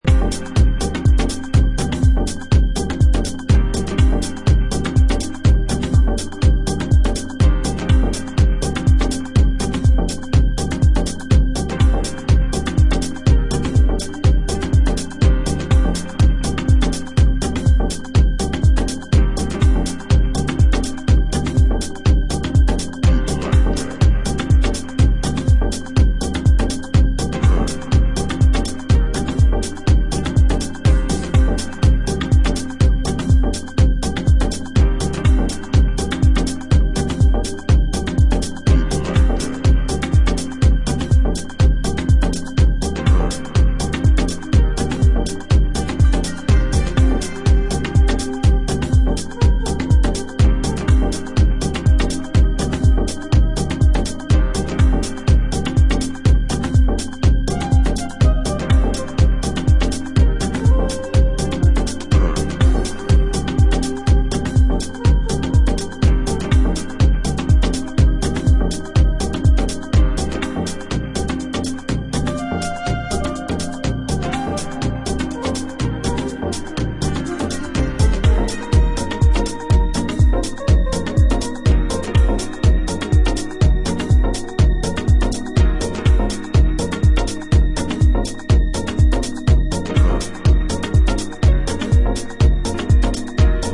3 powerful deep/ techhouse tracks